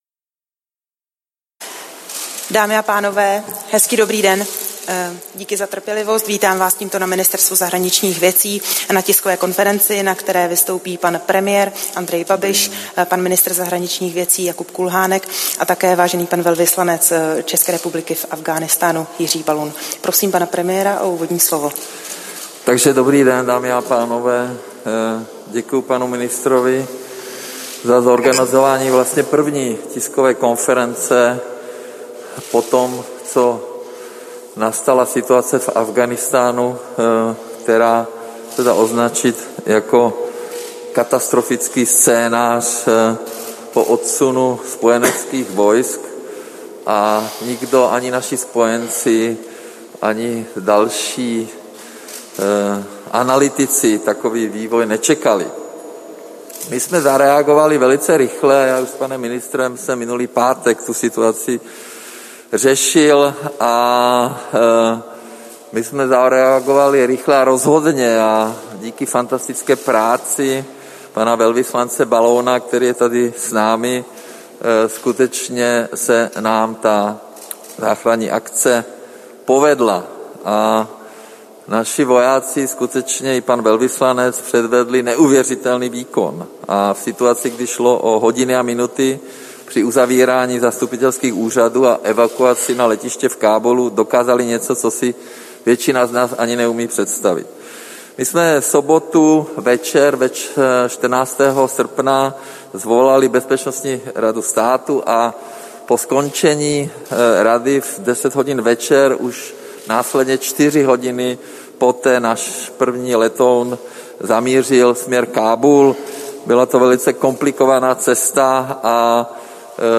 Tisková konference k evakuaci z Afghánistánu, 19. srpna 2021